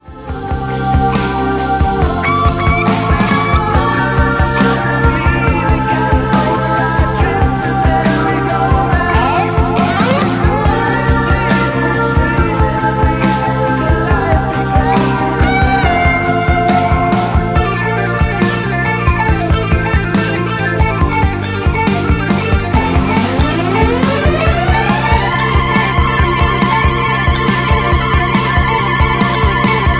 MPEG 1 Layer 3 (Stereo)
Backing track Karaoke
Rock, 1970s